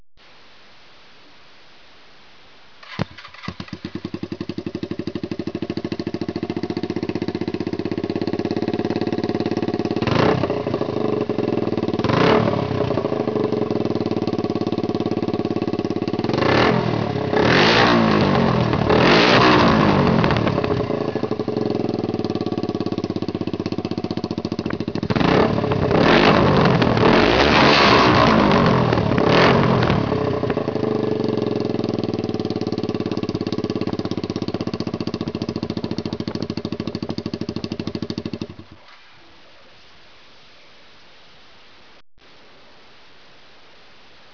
L��chappement �tant un peu trop silencieux � mon go�t, je vais simplement supprimer la